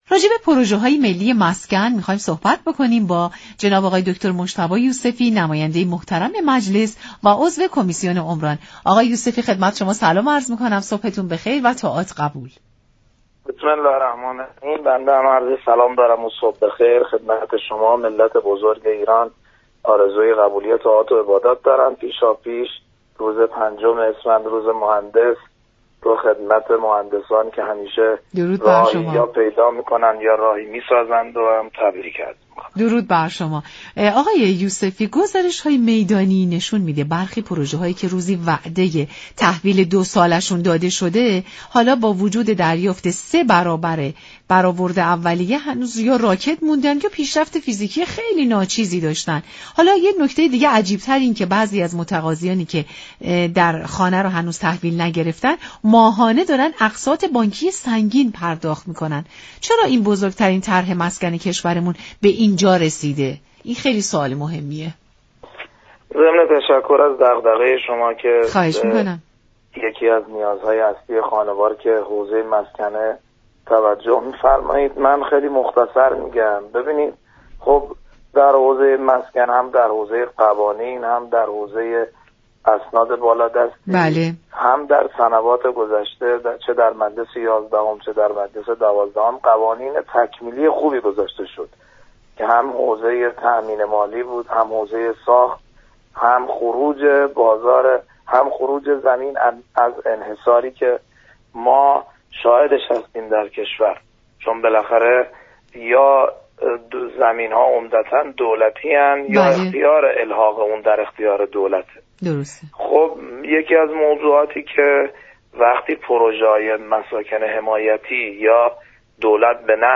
عضو كمیسیون عمران مجلس در برنامه سلام‌صبح‌بخیر گفت: در شرایط امروز كه قیمت مسكن دوبار در سال افزایش می‌یابد؛ هر‌گونه تاخیر در ارائه طرح‌های مسكن، به غیر از آنكه قیمت تما‌م‌شده مردم را افزایش می‌دهد قدرت خرید را كاهش می‌دهد.